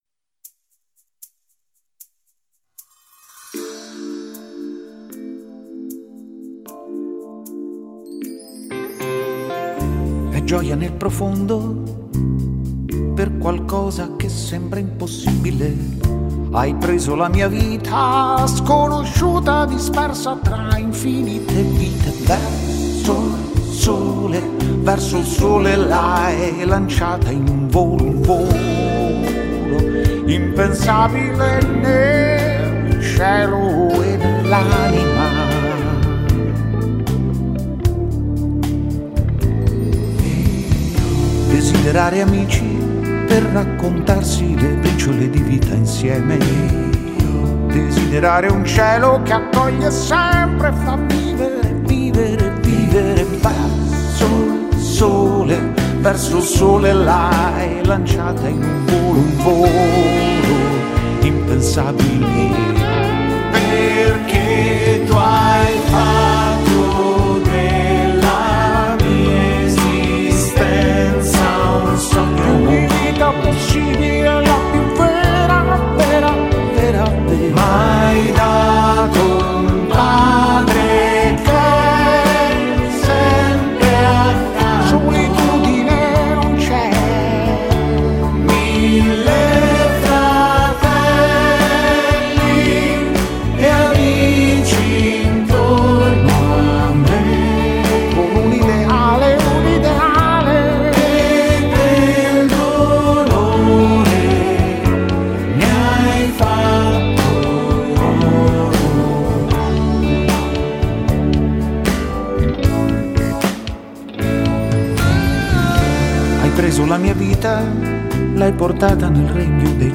concerto che spesso faccio dal vivo